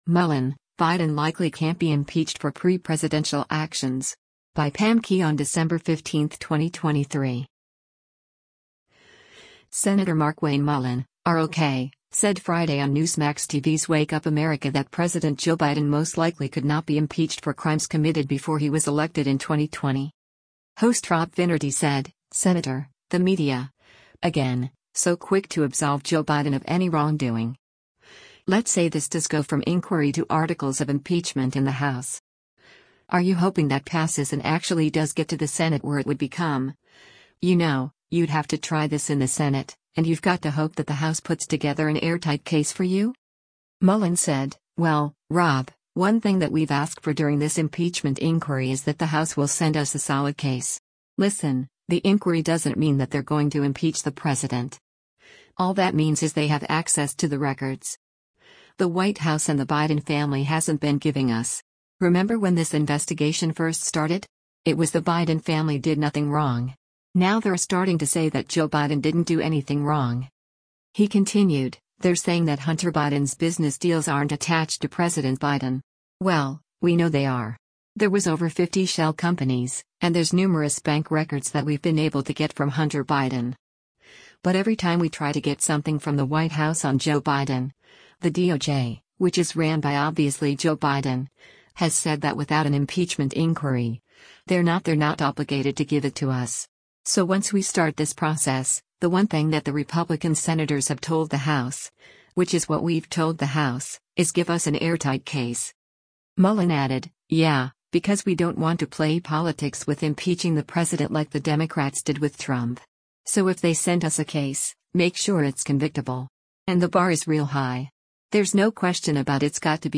Senator Markwayne Mullin (R-OK) said Friday on Newsmax TV’s “Wake Up America” that President Joe Biden most likely could not be impeached for crimes committed before he was elected in 2020.